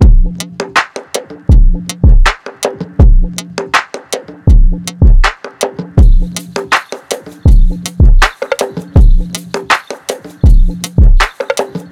Exploration Drums.wav